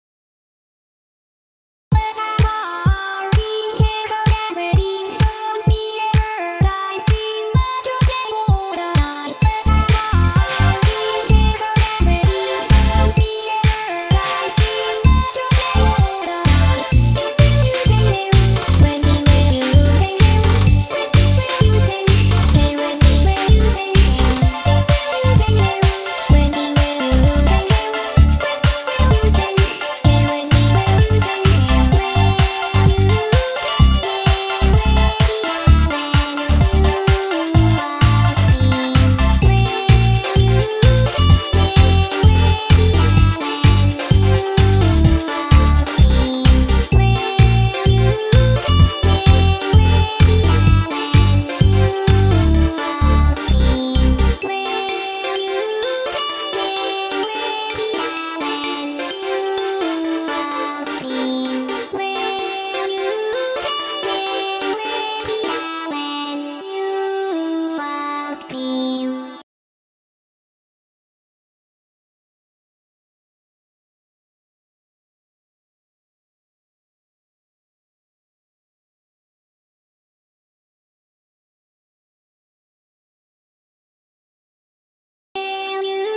Tags: love edm